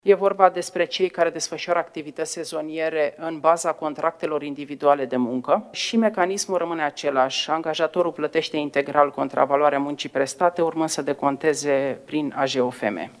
Avem în vedere ca măsura să se adreseze şi persoanelor independente, profesioniştilor, celor care lucrează în alte forme de muncă”, a afirmat Violeta Alexandru, joi, la şedinţa Consiliului Naţional Tripartit pentru Dialog Social.